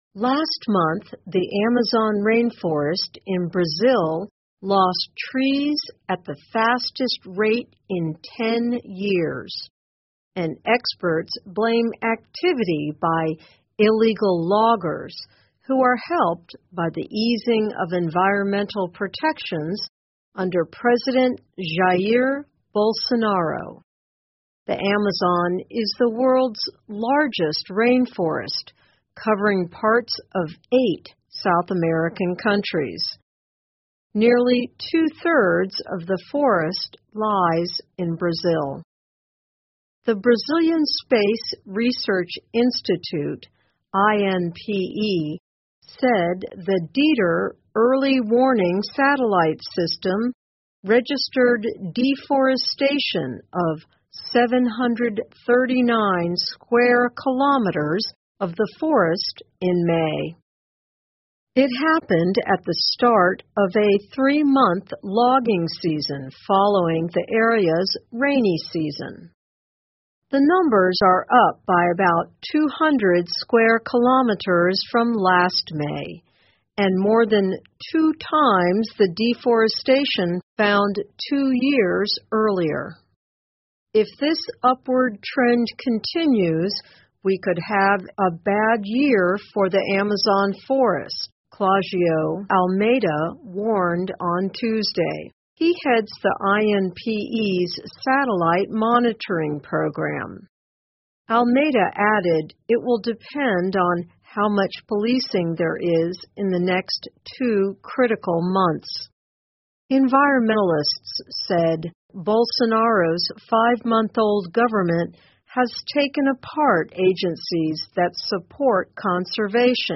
VOA慢速英语--巴西的布尔索纳罗兴起砍伐亚马逊河流域森林 听力文件下载—在线英语听力室